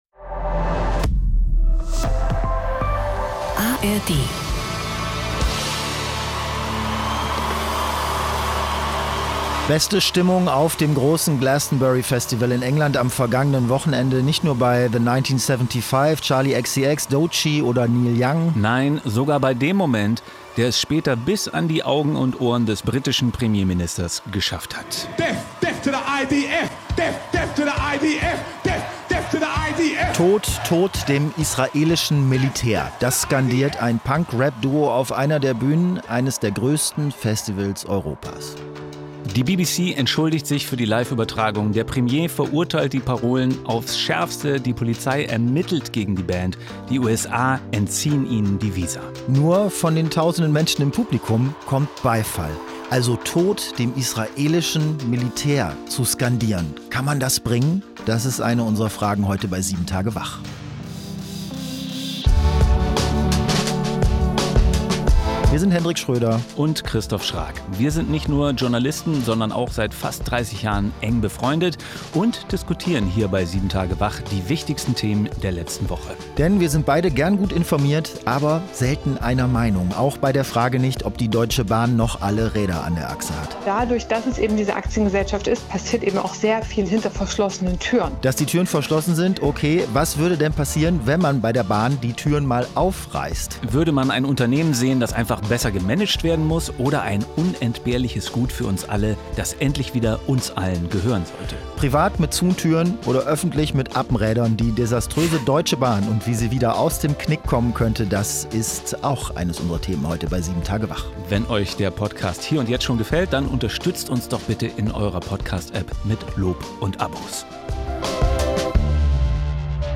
Zwei Freunde, zwei Meinungen, ein News-Podcast: